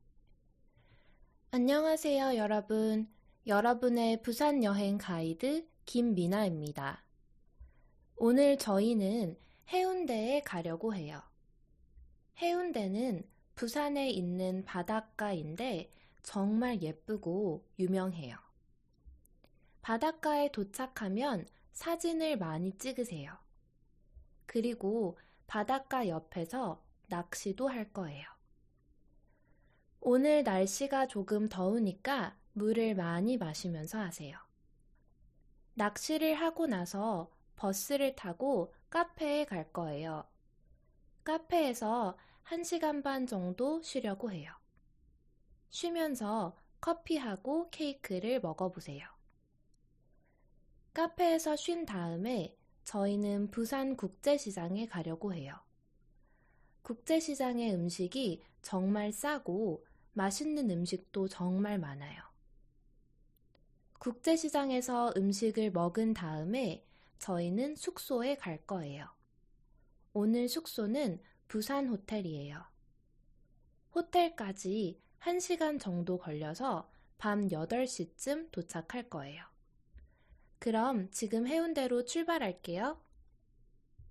• Interpretive communication: Comprehend the tour guide’s announcement on the schedule of Busan tour.